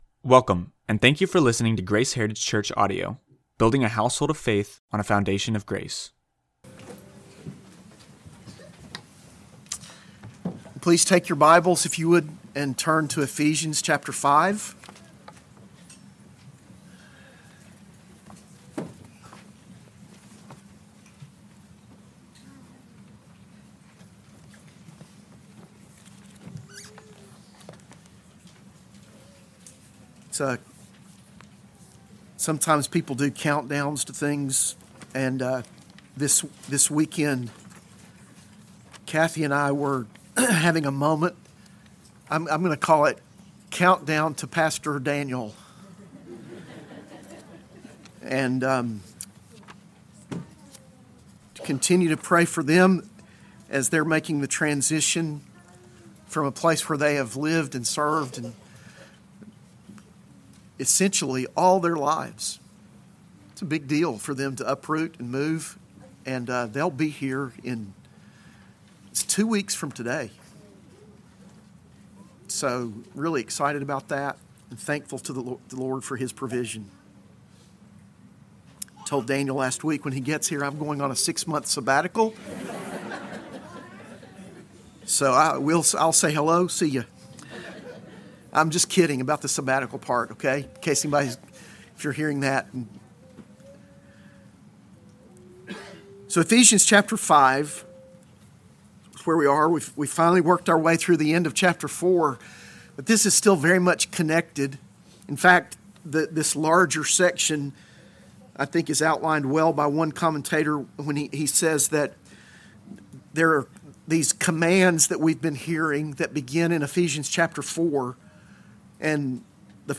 AM Worship Sermon